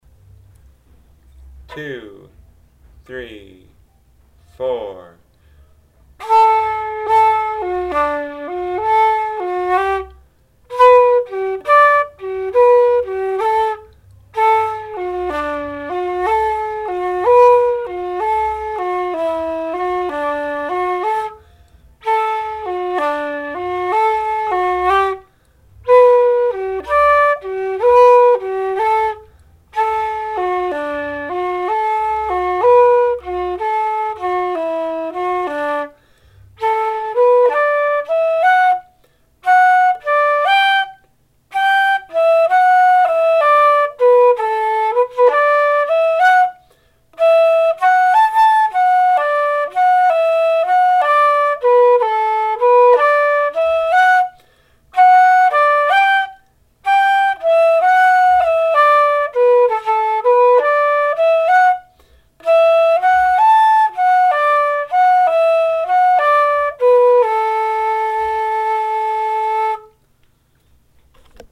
REELS - Rolling in the Rye Grass & The Heathery Breeze
Rolling-in-the-Rye-Grass-slow.mp3